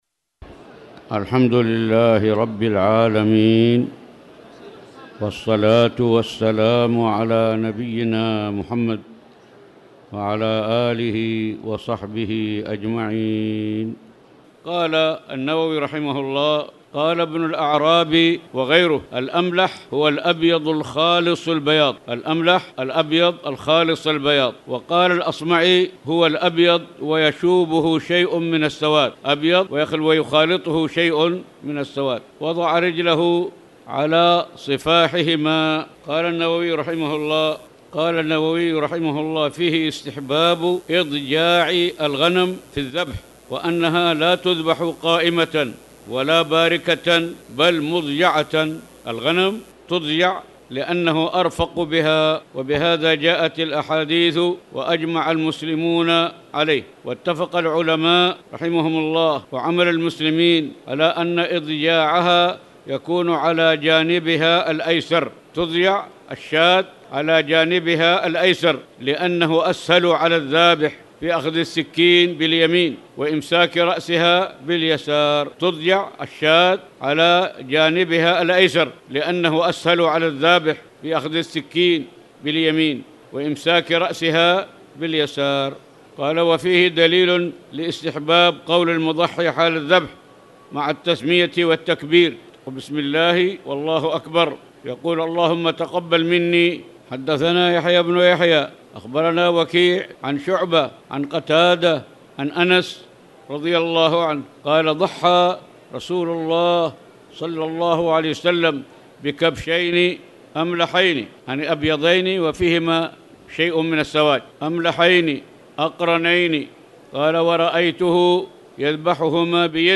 تاريخ النشر ١٧ رمضان ١٤٣٨ هـ المكان: المسجد الحرام الشيخ